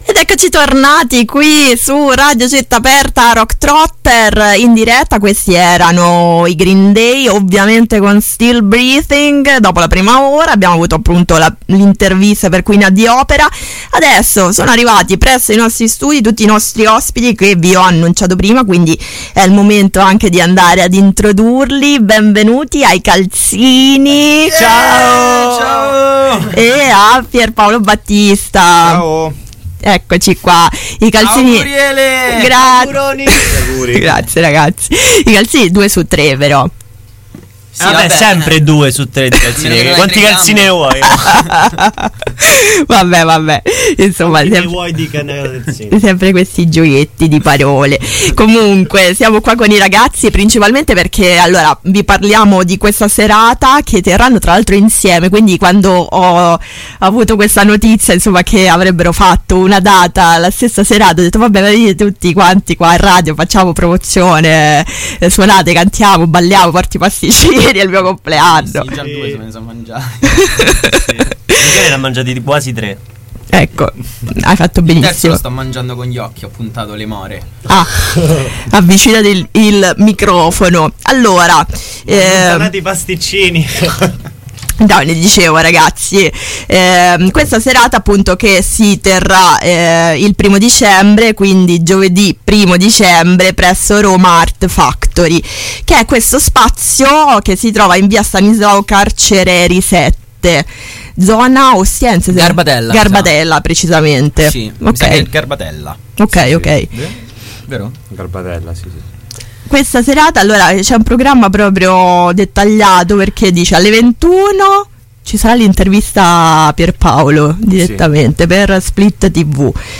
Entrambi hanno suonato dei pezzi per noi, live.